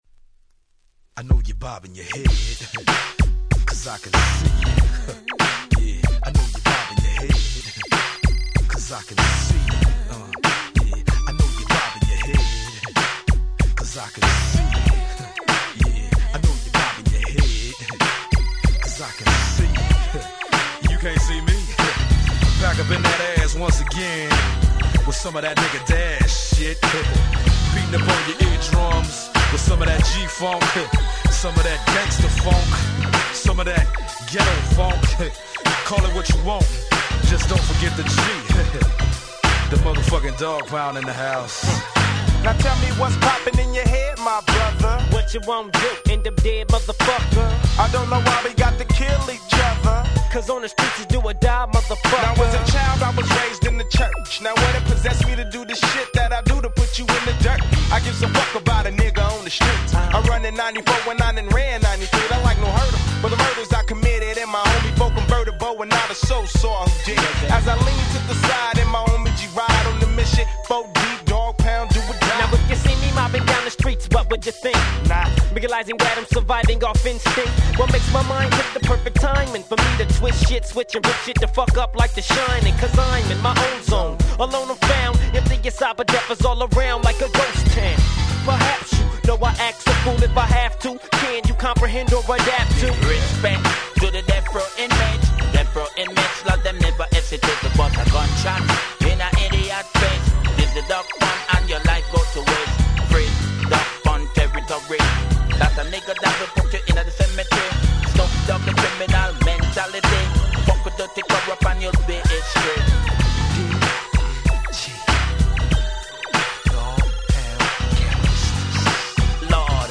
US Promo Only !! 95' Smash Hit West Coast Hip Hop !!